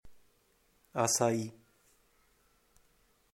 Pronunciation of Açai (Portuguese)